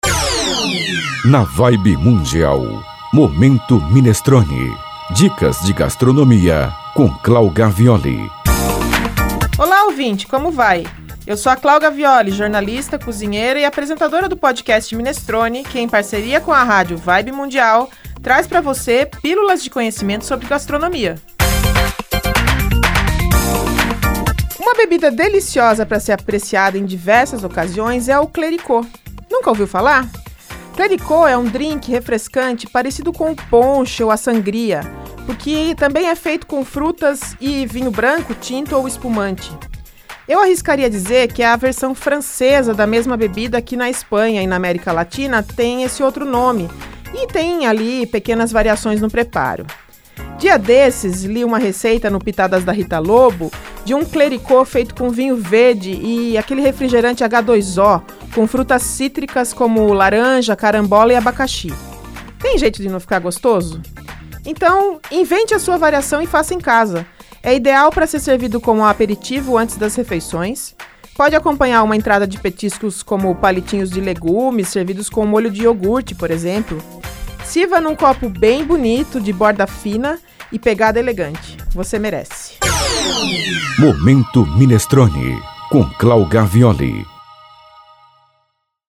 Três vezes ao dia, durante a programação da rádio (às 7h25, 14h25 e 22h25), são veiculadas pílulas de conhecimento em gastronomia.